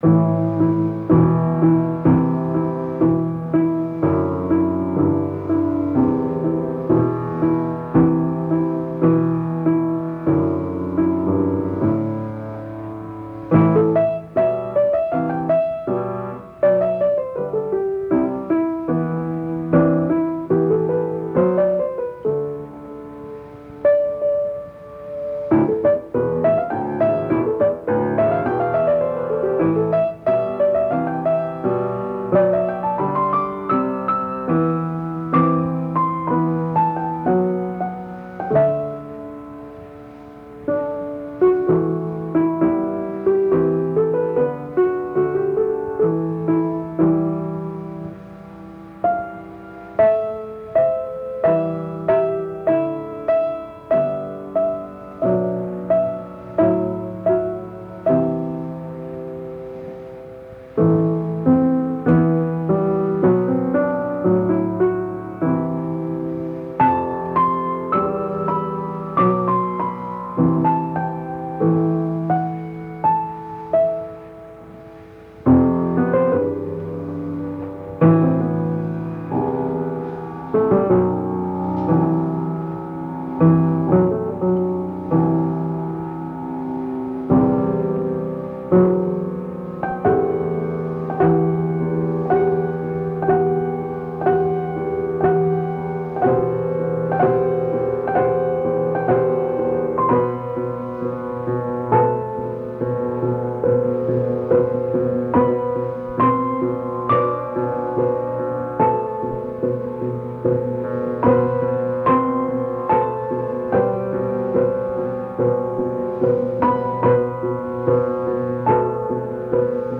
A piano sonata in 3 movements in e minor